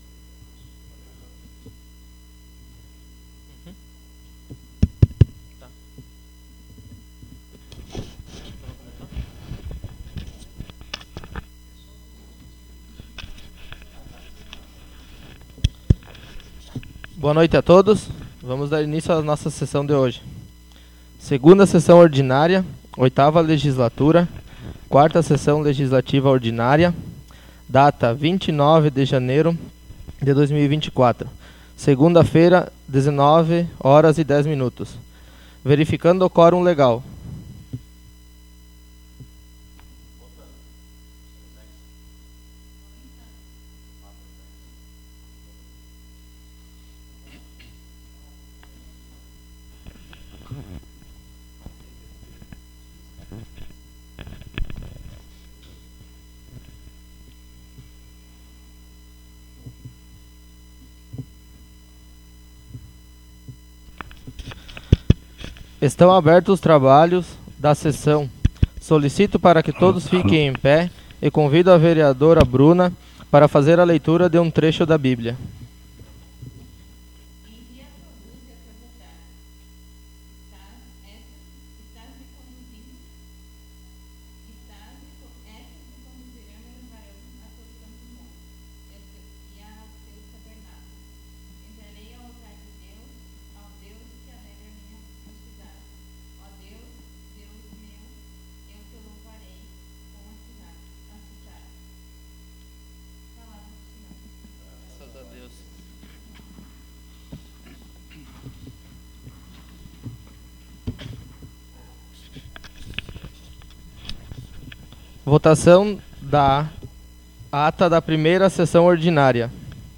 Audio da 2ª Sessão Ordinária - 29.01.24